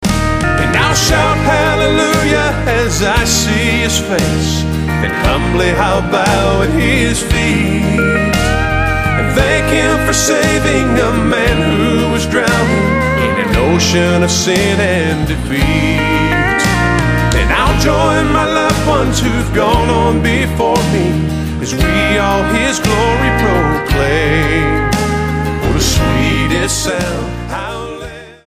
STYLE: Country
crisp production featuring steel guitar, dobros and fiddles